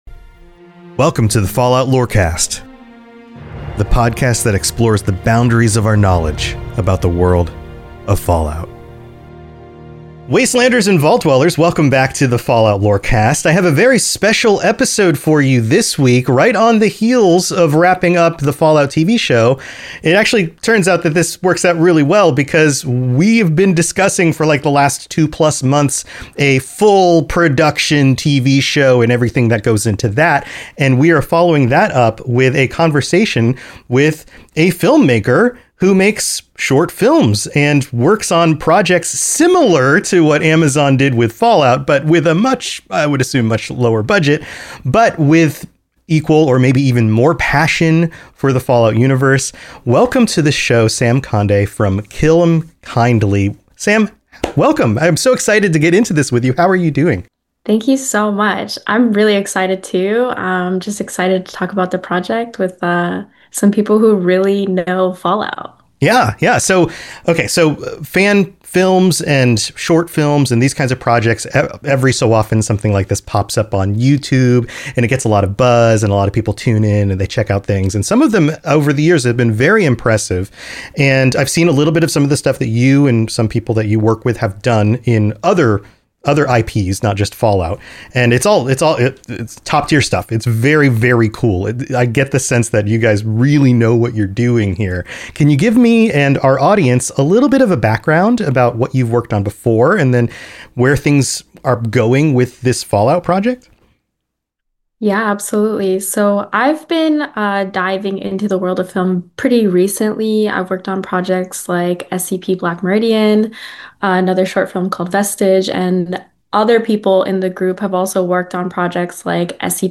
393: Fallout Fan Film "Kill 'em Kindly" Interview w